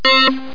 beep2.mp3